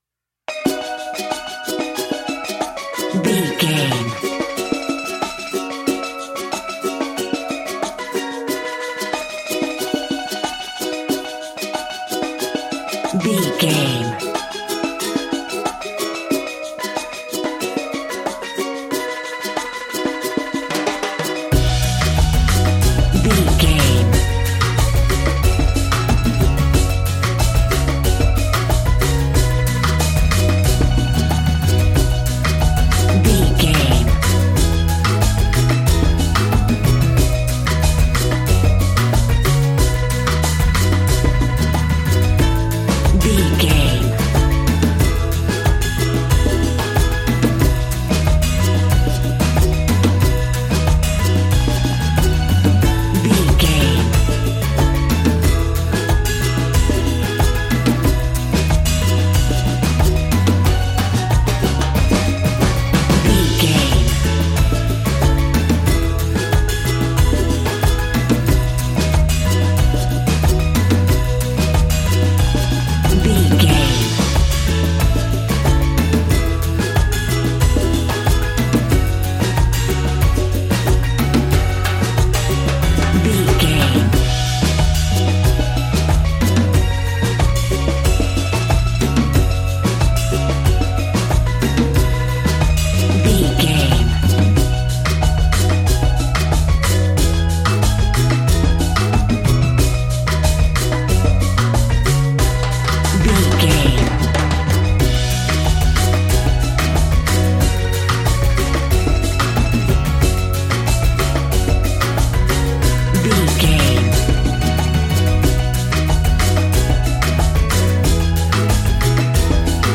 Ionian/Major
cheerful/happy
mellow
drums
electric guitar
percussion
horns
electric organ